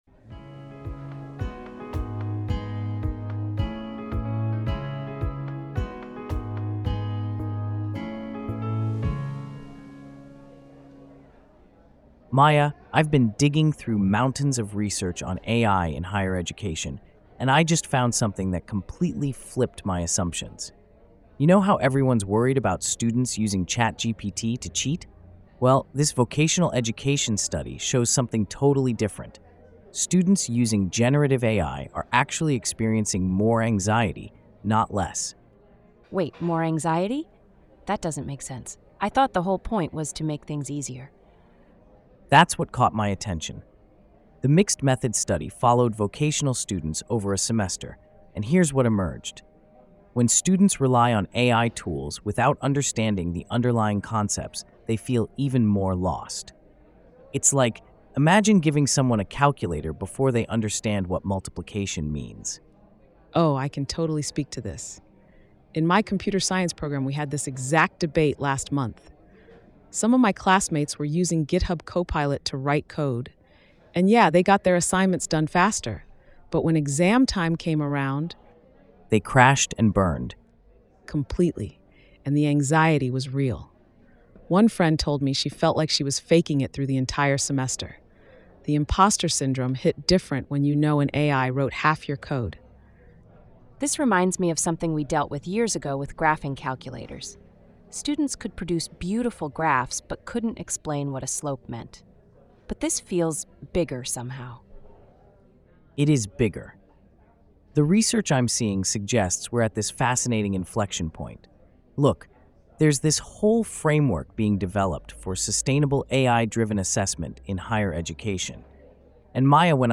Knowledge & Skills Discussion